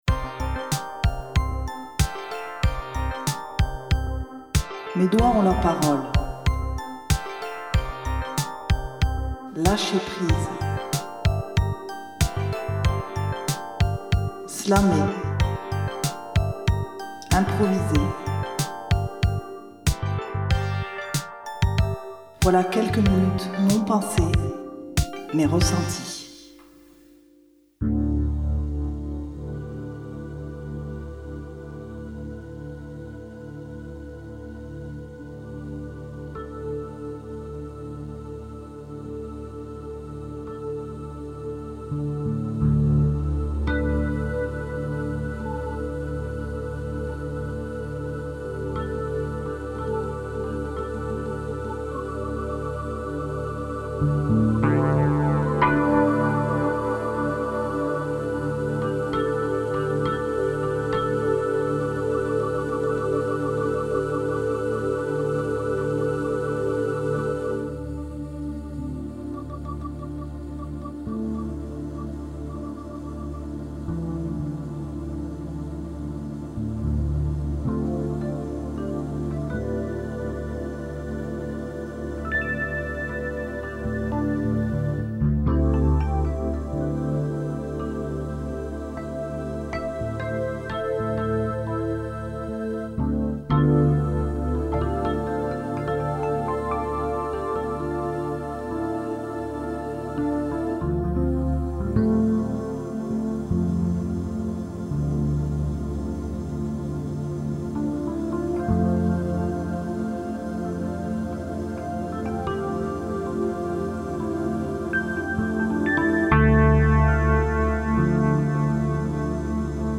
8e épisode d'impro